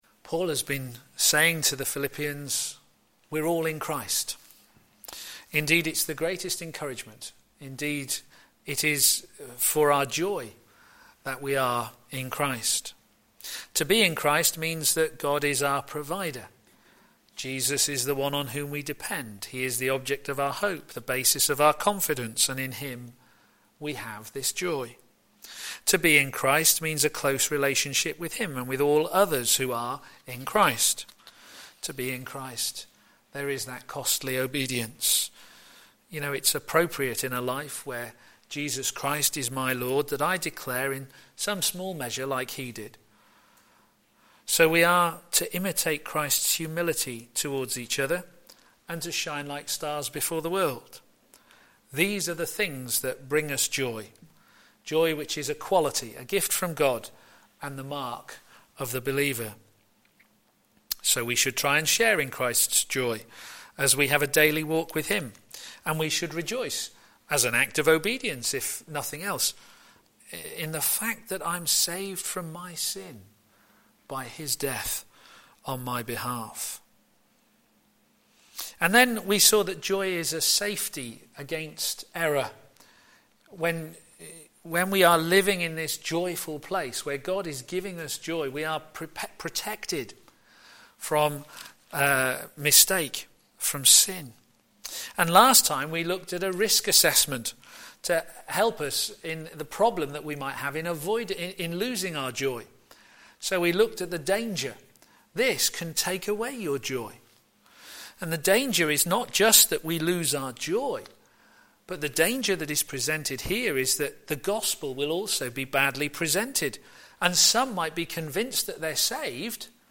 Series: Living the life of joy Theme: Removing the risks of a joyless life Sermon